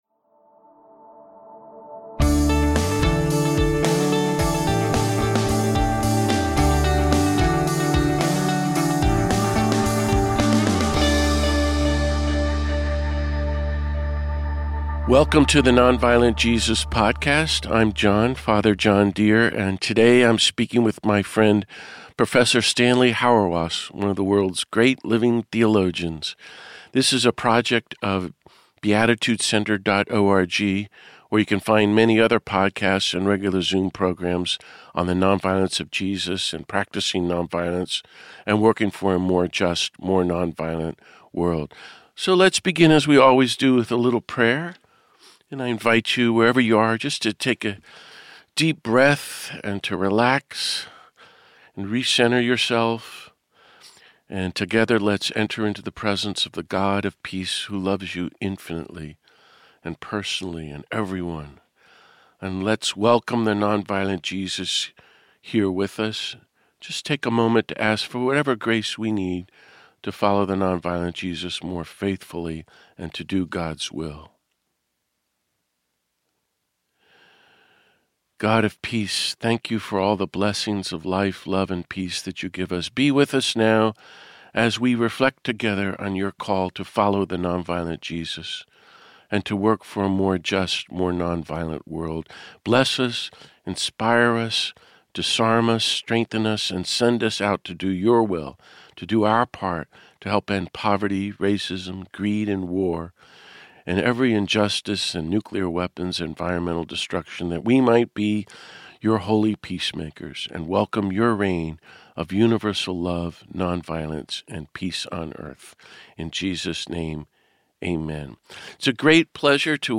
This week I speak with world renown theologian and ethicist Professor Stanley Hauerwas.